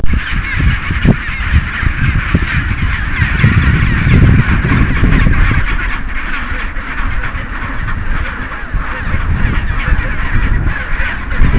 The unbelievable number of penguins